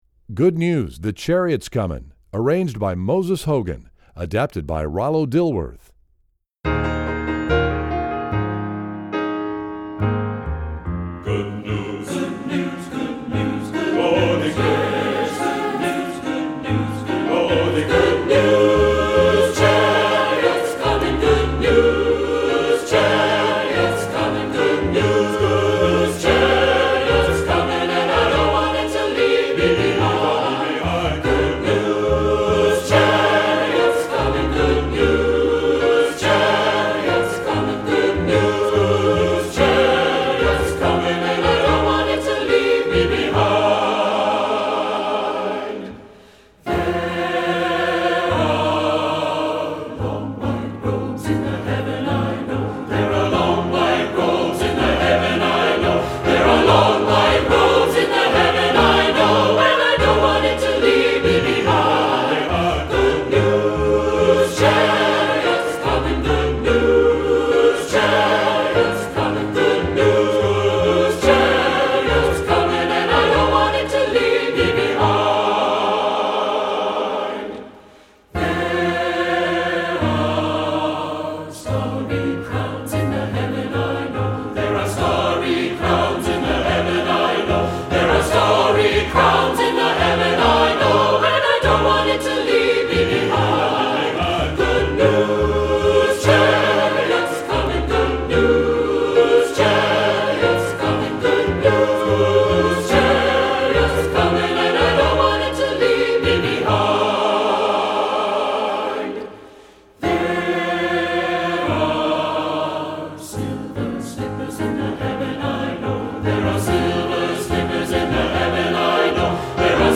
Composer: Spiritual
Voicing: SATB